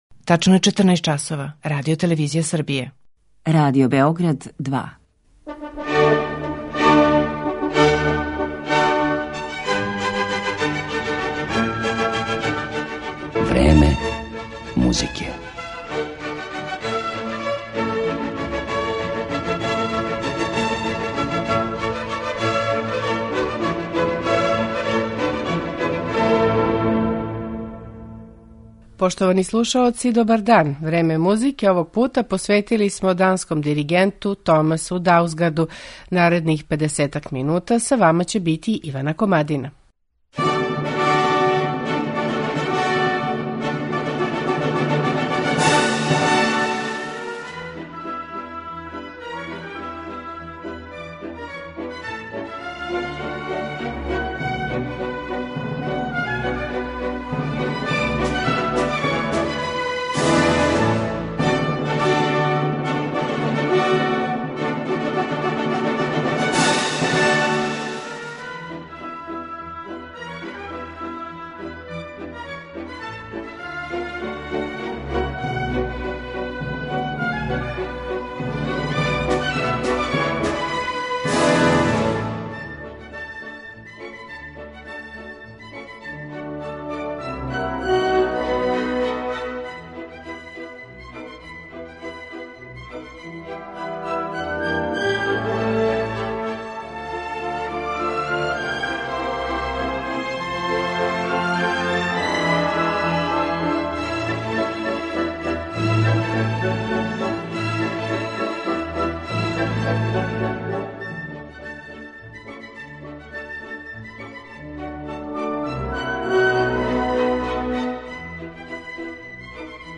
У данашњем ВРЕМЕНУ МУЗИКЕ, које смо посветили овом данском уметнику, чућете његове интерпретације дела Бетовена, Мартинуа, Шуберта и нордијских аутора: Јохана Свендсена, Асгера Хамерика и Франца Бервалда.